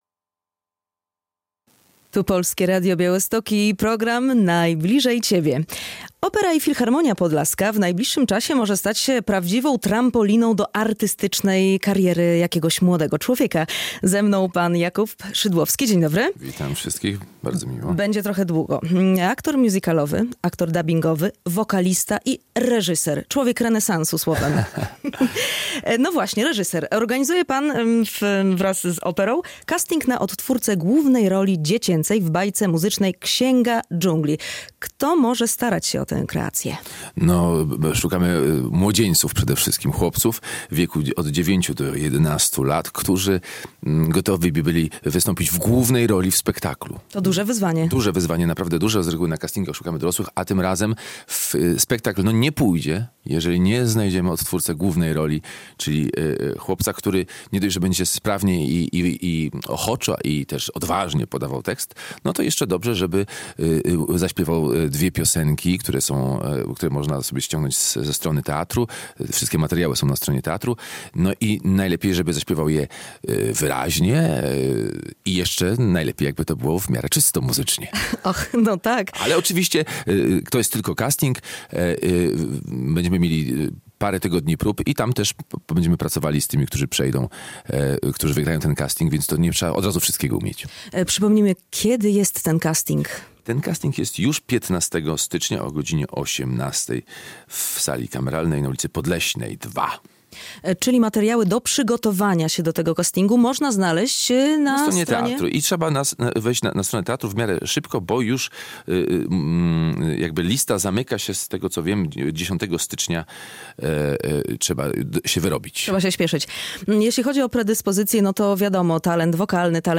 GOŚĆ RADIA BIAŁYSTOK